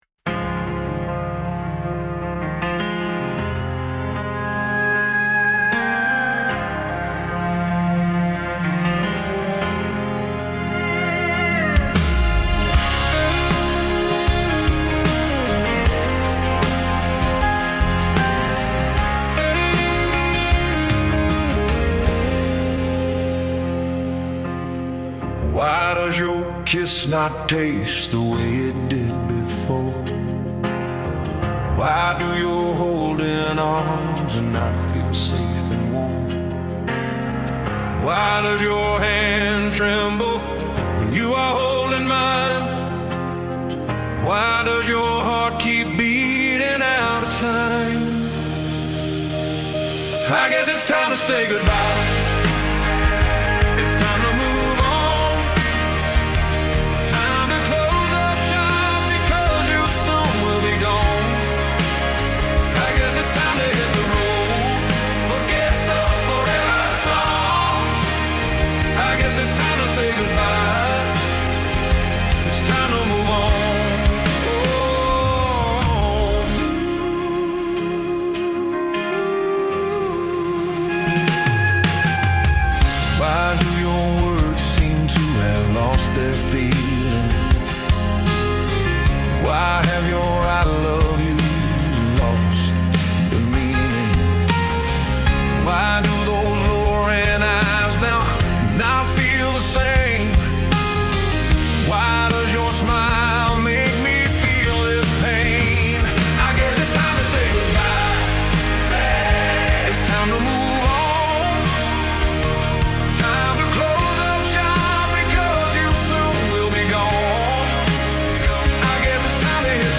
Country